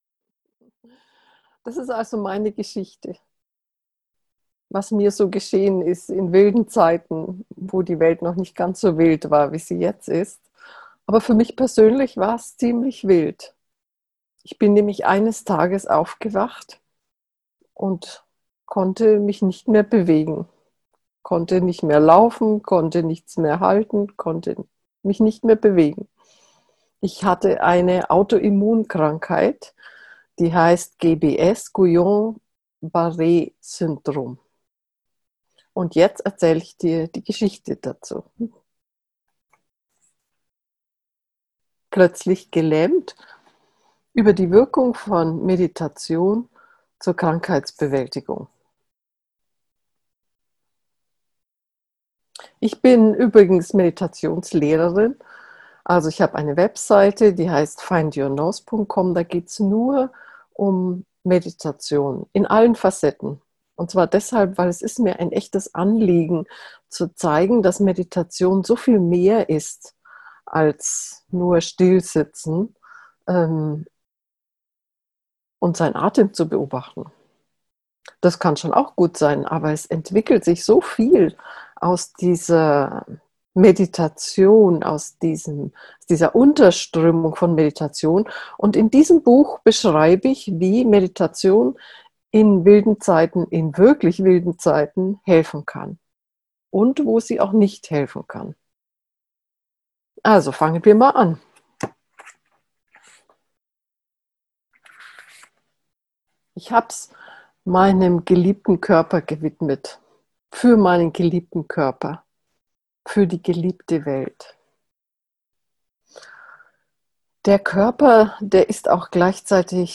Buchlesung